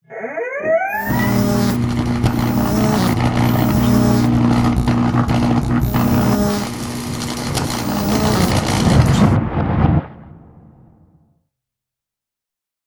shock.wav